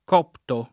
copto [ k 0 pto ] (raro cofto )